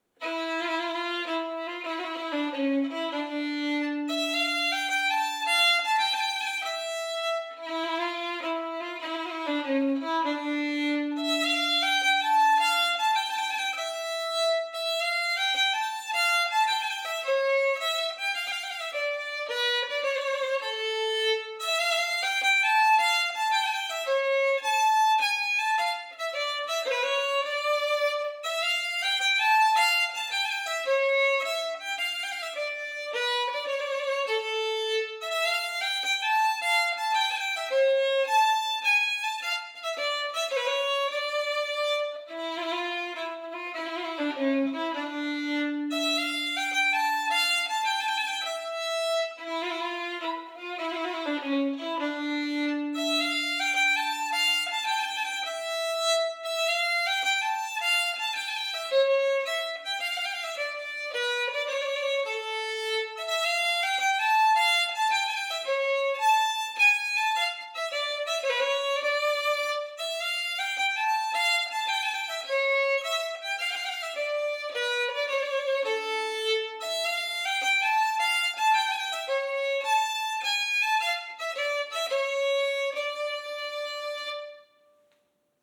Vispolska, fiolmelodi och hornlåt